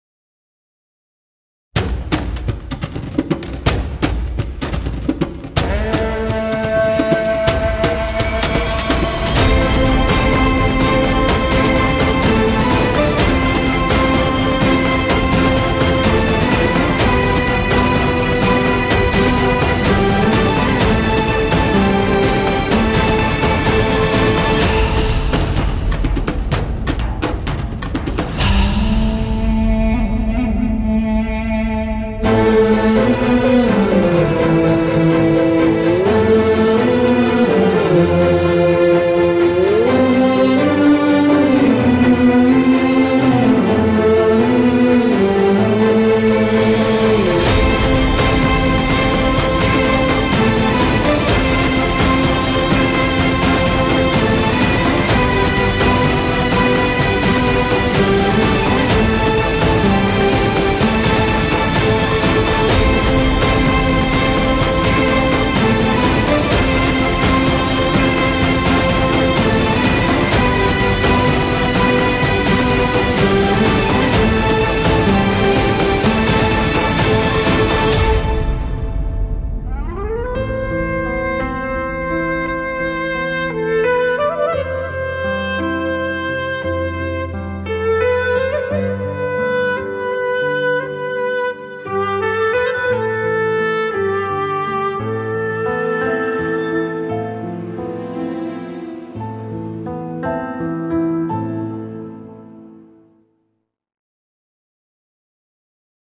tema dizi müziği, duygusal huzurlu heyecan fon müziği.